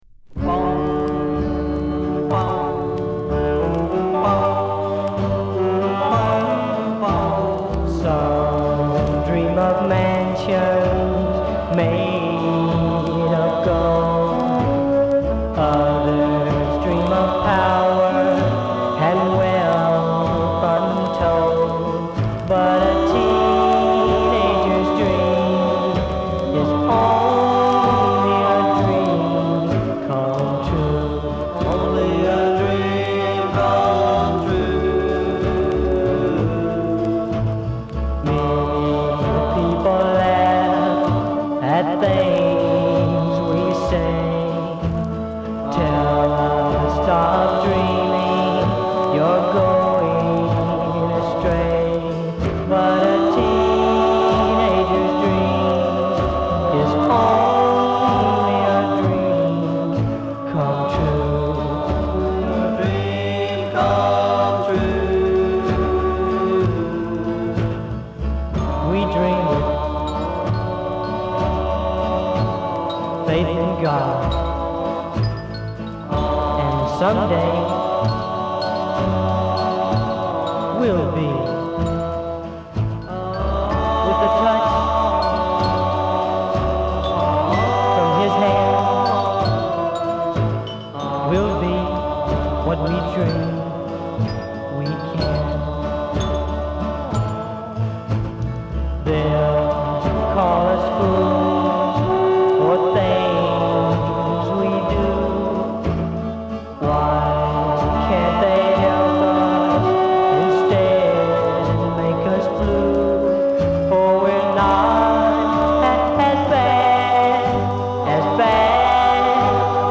プライヴェート盤らしいプリミティヴな音質がグレート。B面はぼやけた感じのティーン・バラードを収録。